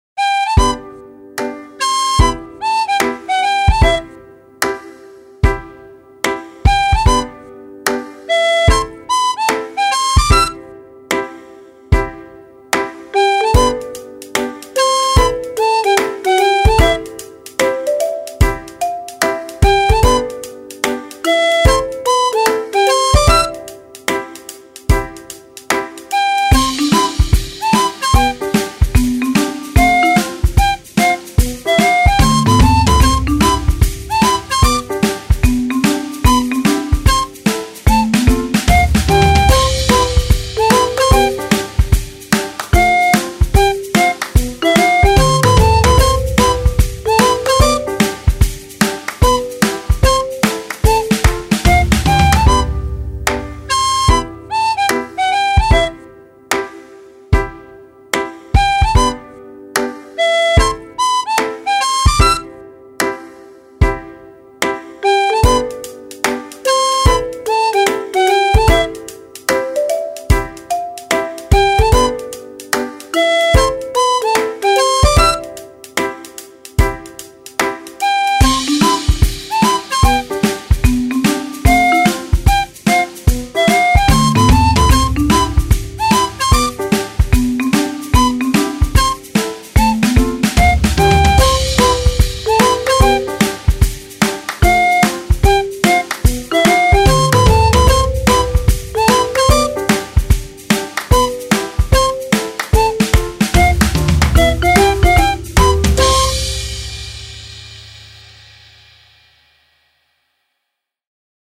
何気ない日常を表現した、気楽な雰囲気のBGMです。